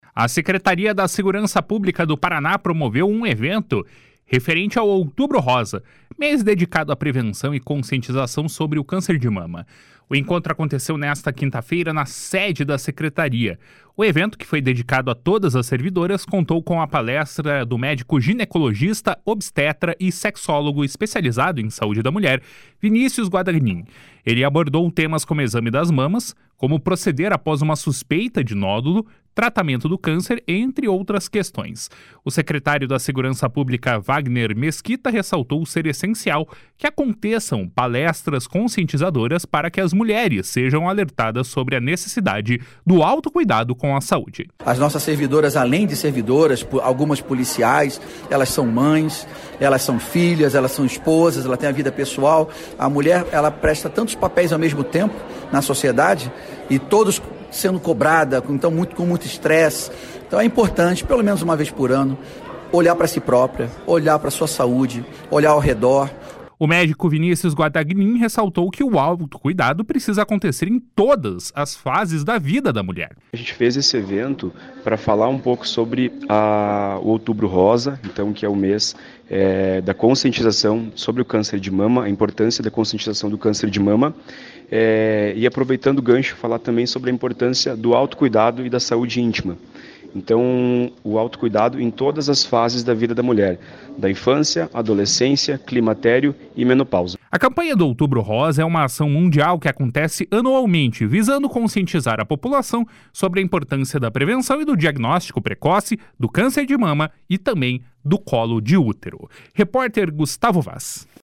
// SONORA WAGNER MESQUITA //